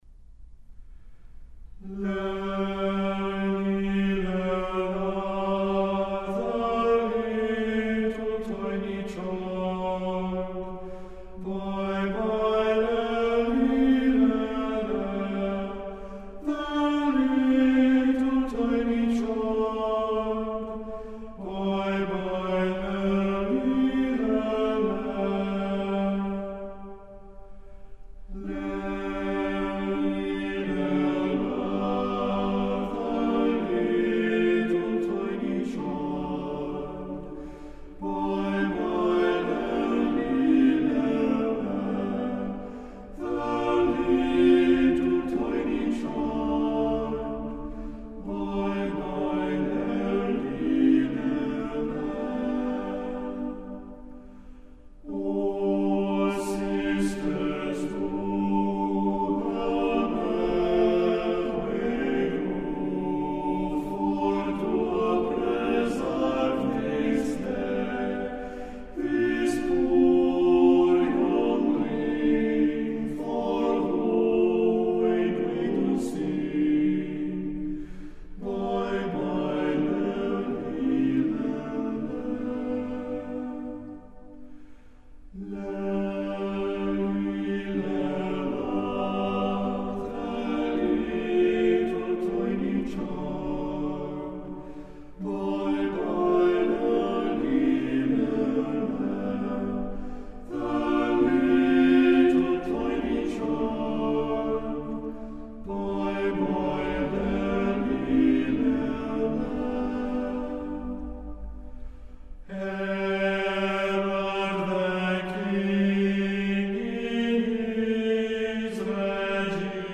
six-man vocal ensemble